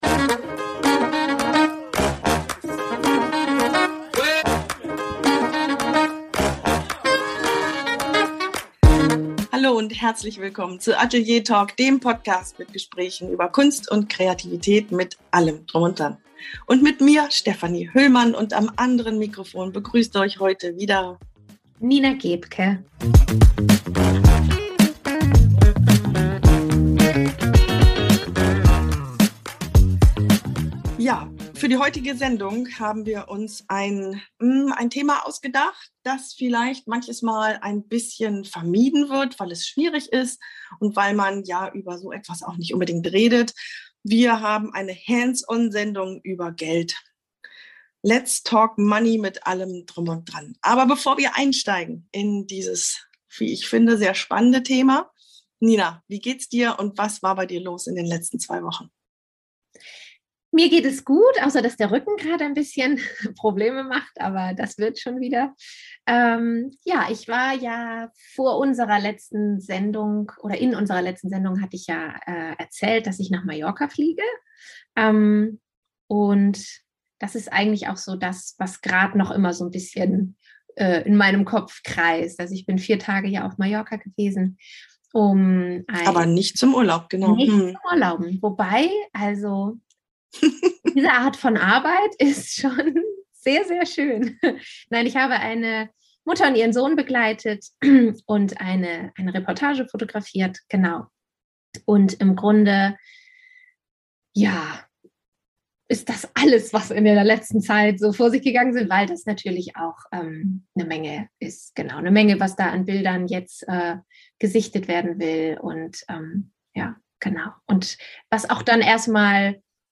Ein Gespräch über Fakten, Mindest und Mut